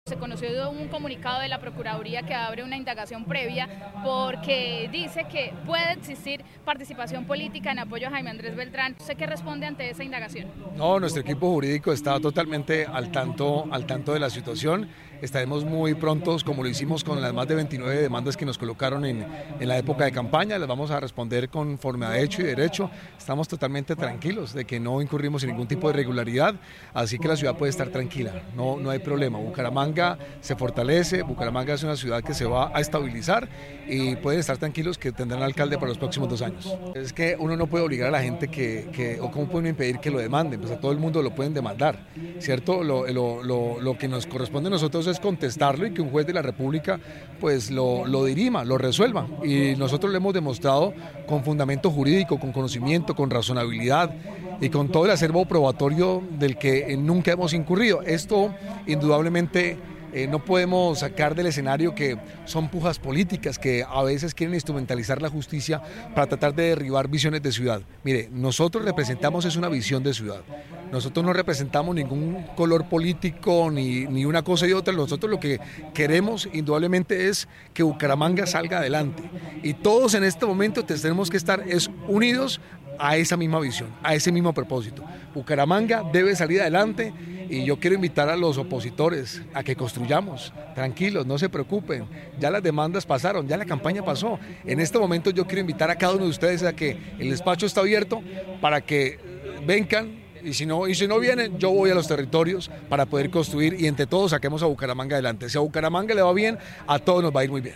Cristian Portilla, alcalde de Bucaramanga